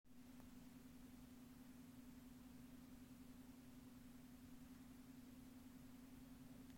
Bruit moteur ventilateur clim Atlantic Fujitsu
Depuis, quand le ventilateur de l'UE tourne à bas régime, on entend comme une vibration jusque dans la maison.
Vous pouvez l'écouter via le fichier audio (désolé la qualité n'est pas top, bien monter le son pour entendre ce bruit cyclique).
doublon 752336 bruit clim.mp3